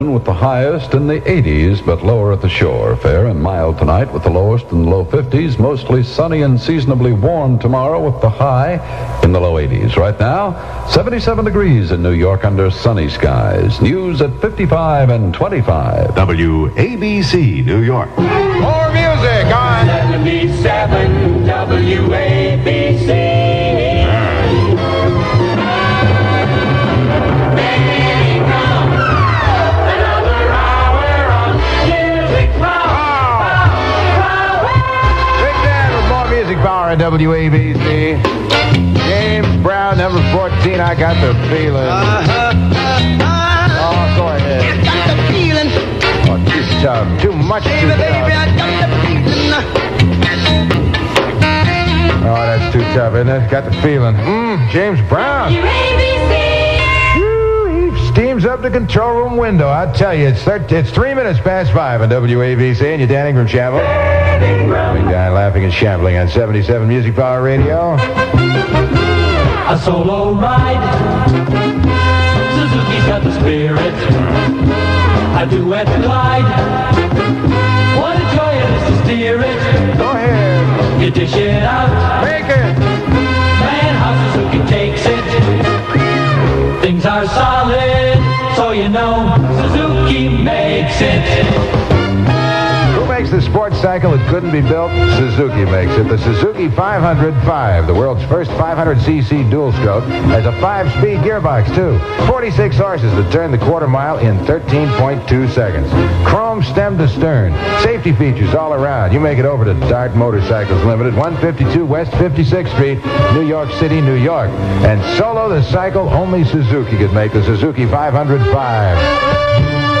Talent: DAN INGRAM Station: WABC New York
For nearly a half-century, the Oceanside, N.Y., native weaved double entendres, satire and wit and into a fast-paced Top 40 radio patter unmatched in the industry.